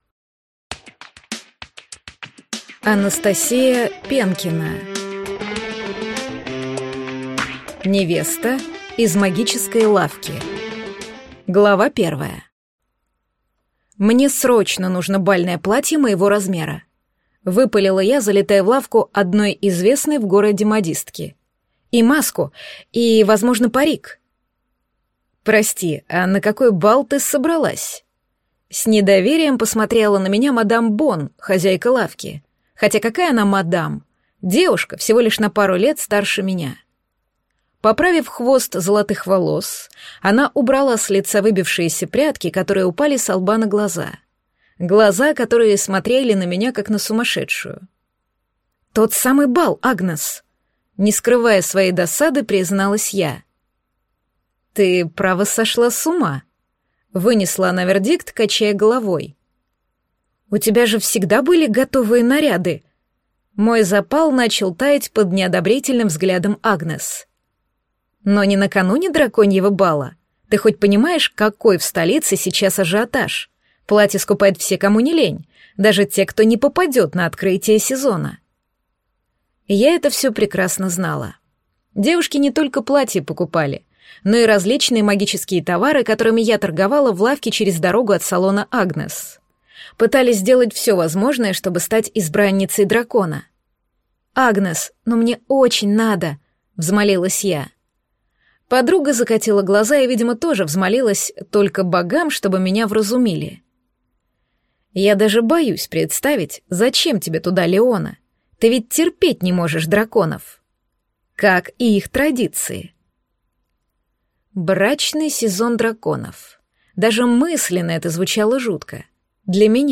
Аудиокнига Невеста из магической лавки | Библиотека аудиокниг